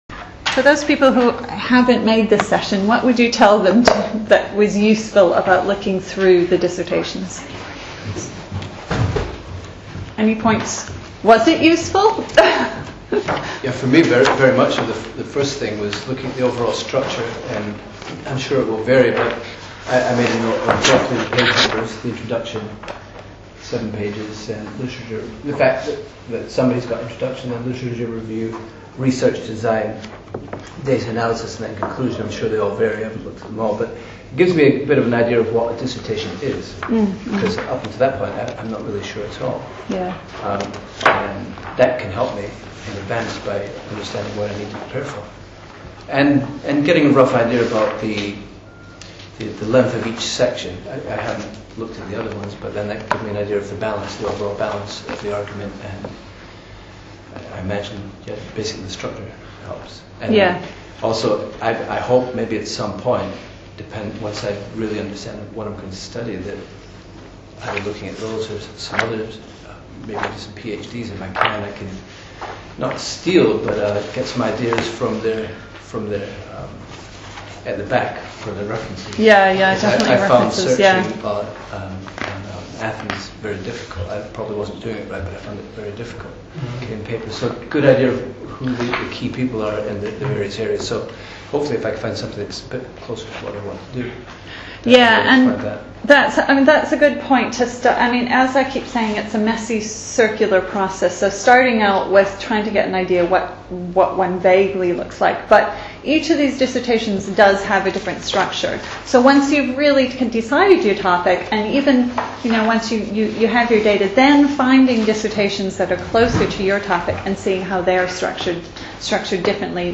Latest Episode MA dissertation seminar 21 Jan 2010 Download the latest episode Note: in some browsers you may have to wait for the whole file to download before autoplay will launch. These are recordings from sessions on the IOE/LKL MA Media which support distance learners These are recordings from sessions on the IOE/LKL MA Media which support distance learners.